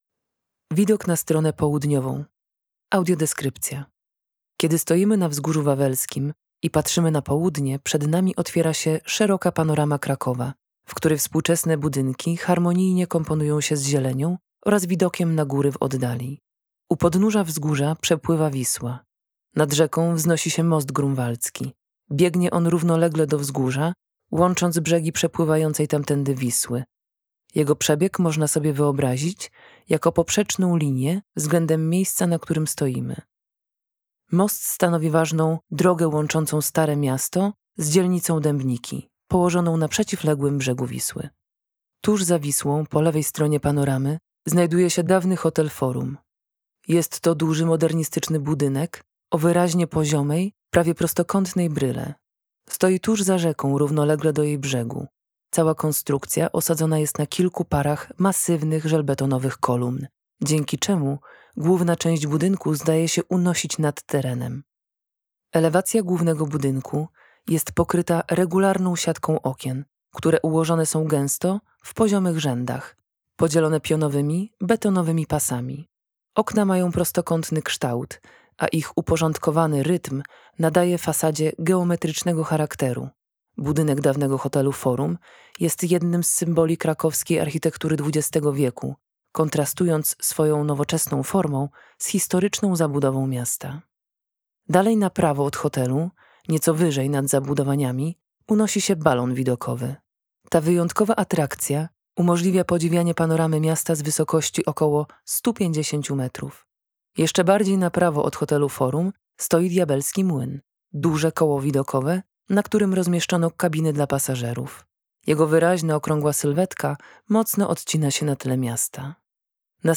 Audiodeskrypcje widoków z Wawelu - POŁUDNIE - Zamek Królewski na Wawelu - oficjalna strona - bilety, informacje, rezerwacje